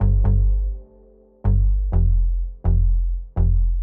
低于125BPM的低音循环
描述：下面的循环的低音 使用的音阶是D大调 更多免费循环鼓套件请查看我的个人资料描述。
Tag: 125 bpm Trap Loops Bass Synth Loops 661.64 KB wav Key : Unknown FL Studio